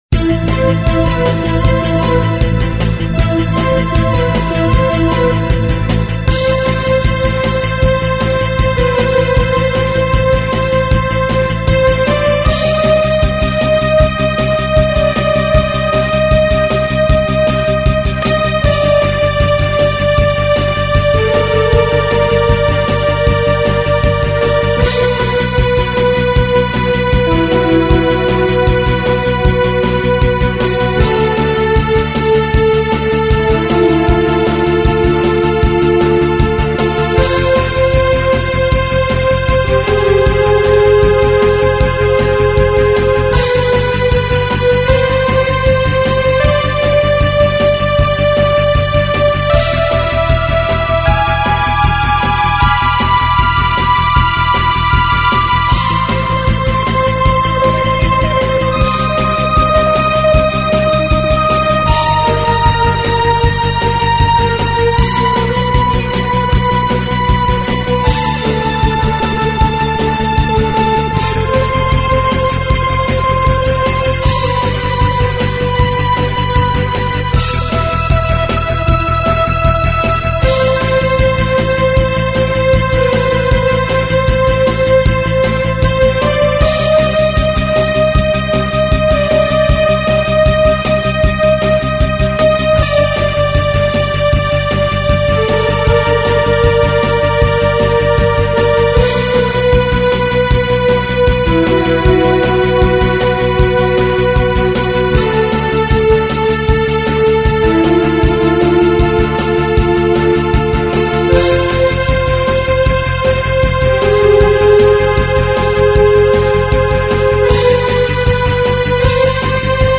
Музыка в стиле New Age